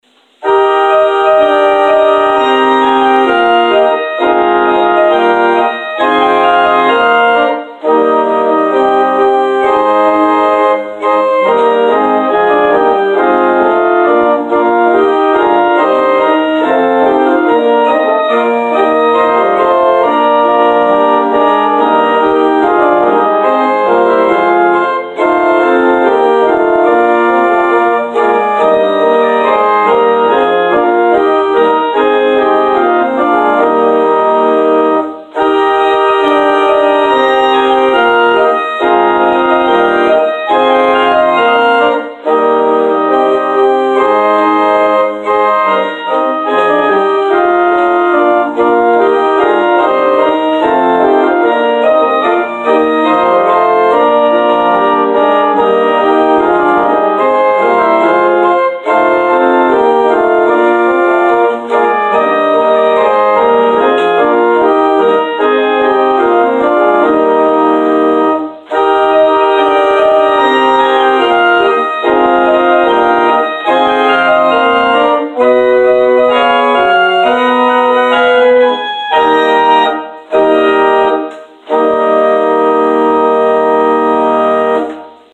Prelude: “Andante Cantabile” from Symphony No. 5 – Peter Tschaikowsky